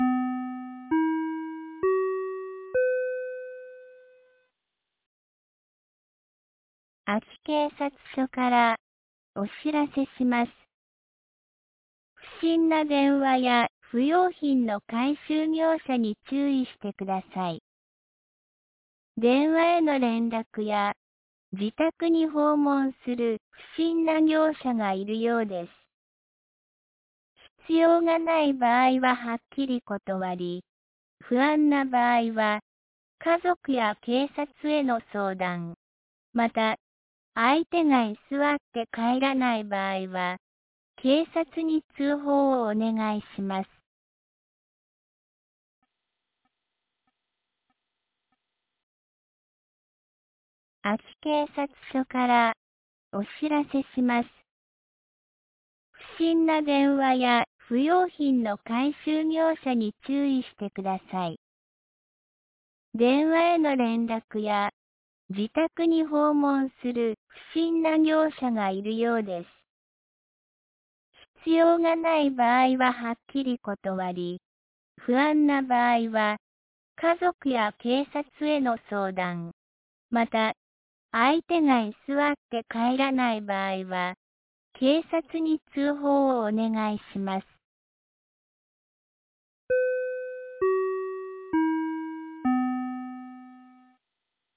2024年12月19日 17時11分に、安芸市より全地区へ放送がありました。